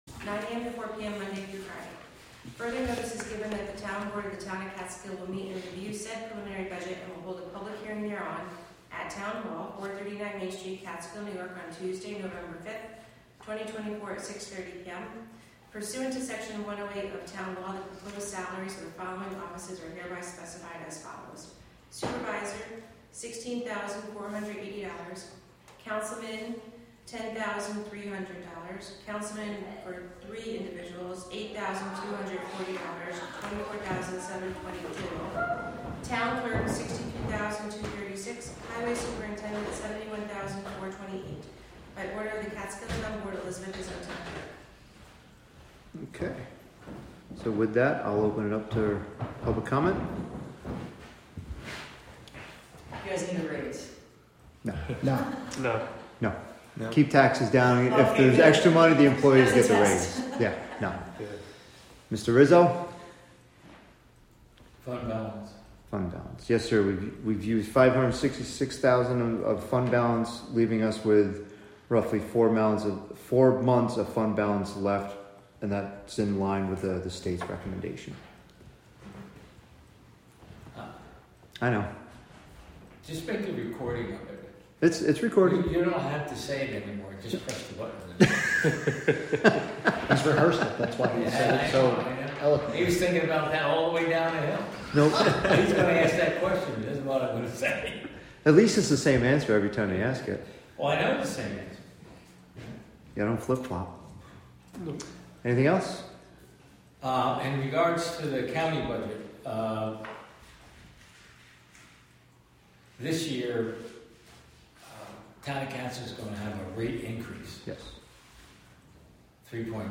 Live from the Town of Catskill: November 5, 2024 Catskill Town Board Meeting (Audio)